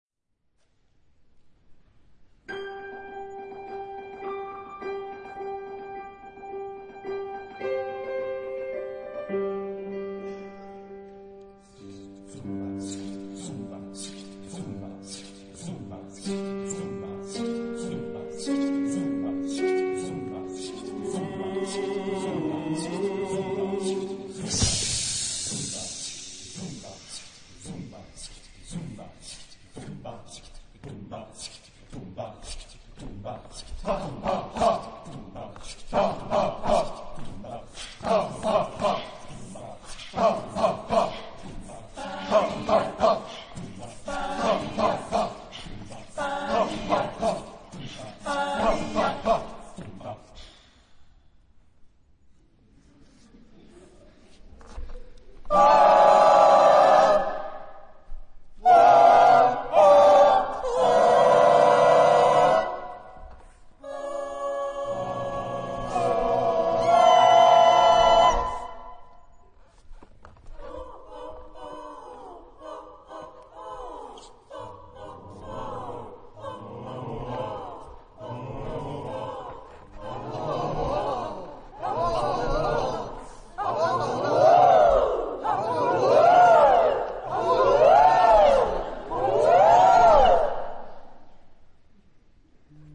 Texte en : onomatopées
Genre-Style-Forme : Jazz choral
Caractère de la pièce : rythmé
Type de choeur : SMATB  (5 voix mixtes )